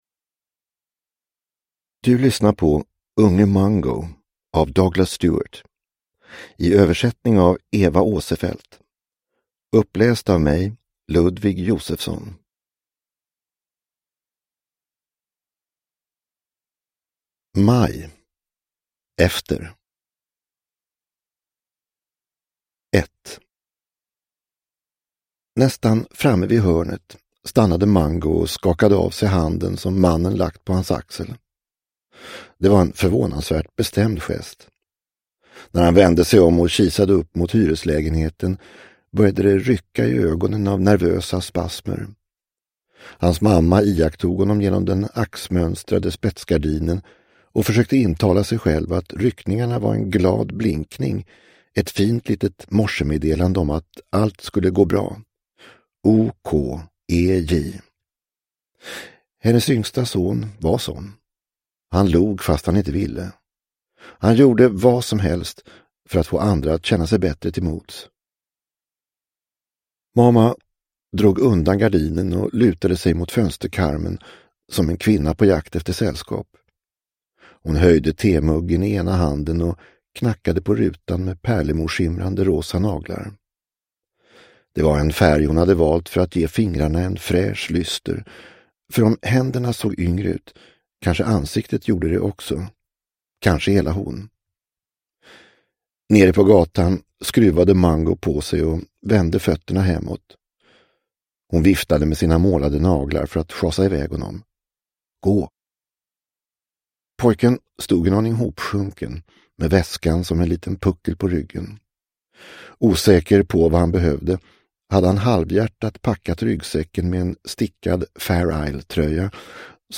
Unge Mungo – Ljudbok – Laddas ner